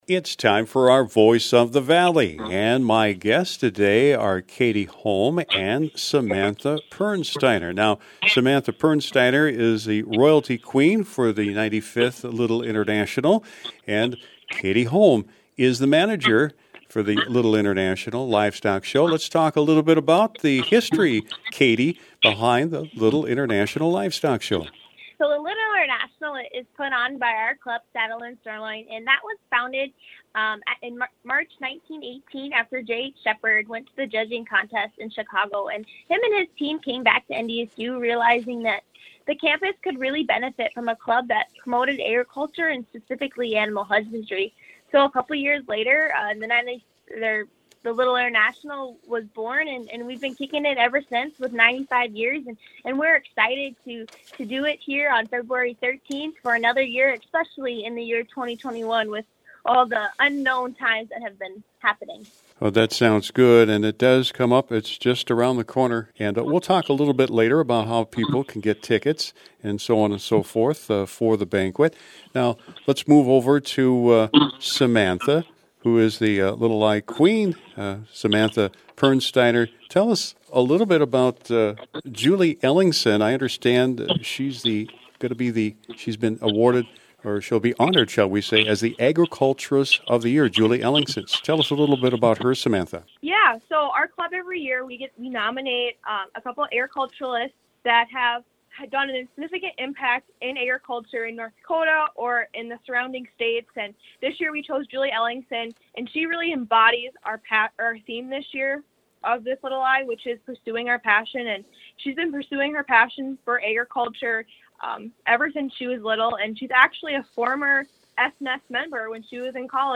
LITTLE-INTERNATIONAL-RADIO-SHOW.mp3